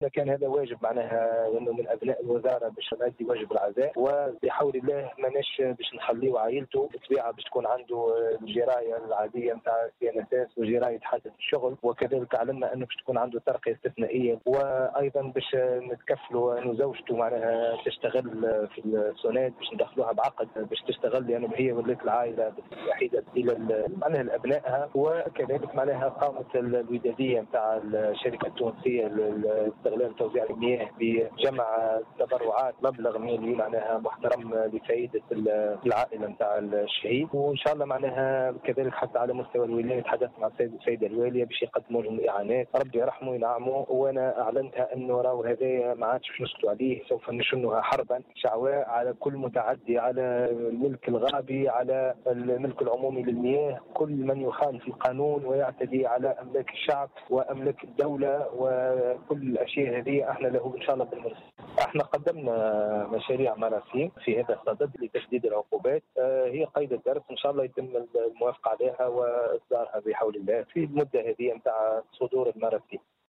وأبرز في هذا السياق، في تصريح لمراسلة الجوهرة أف أم، أن عائلته ستتمتع بالجراية التي يصرفها الضمان الإجتماعي، بالإضافة إلى جراية حادث الشغل، مع حصول الفقيد على ترقية استثنائية، وإعانات مادية من قبل الولاية.